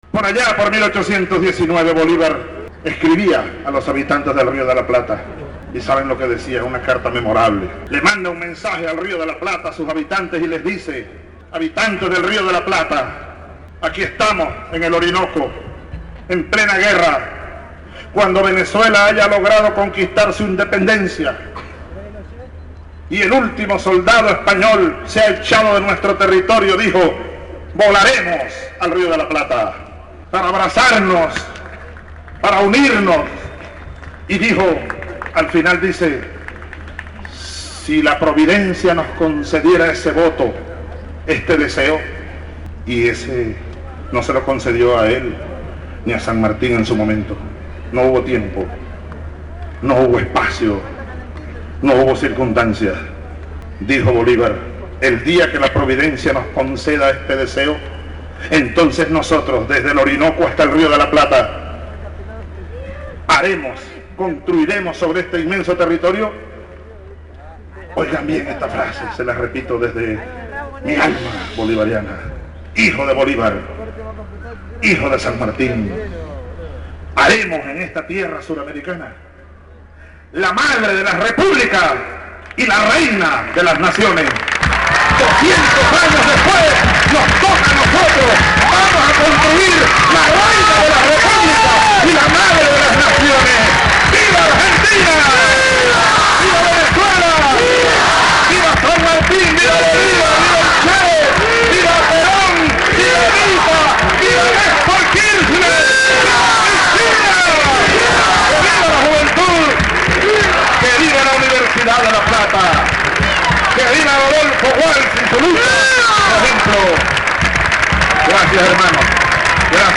Señaló el presidente venezolano Hugo Chavez Frías al recibir, en el marco de un acto multitudinario, el «Premio Rodolfo Walsh» por su compromiso como «Presidente Latinoamericano por la Comunicación Popular»
El acto tuvo lugar en el predio de la Facultad, donde se levantó un escenario para la ocasión, al que concurrieron personalidades del ámbito local, funcionarios de naciones latinoamericanas y una multitud de jóvenes que le plasmó a la noche un clima de emoción y alegría.